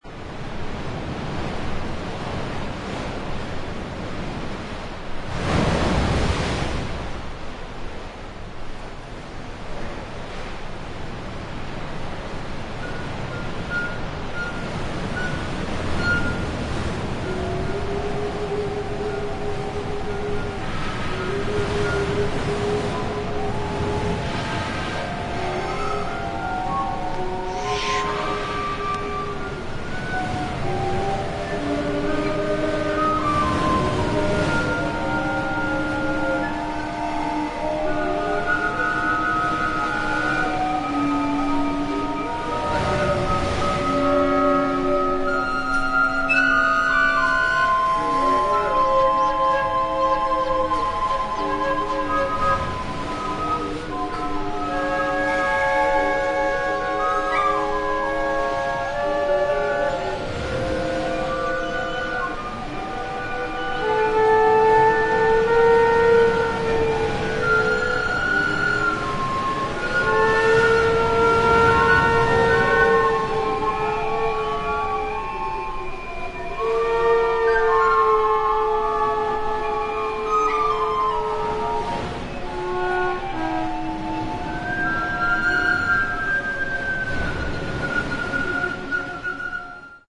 本作は、フェロー諸島にある断崖の渓谷の洞窟でレコーディングされたライヴ音源。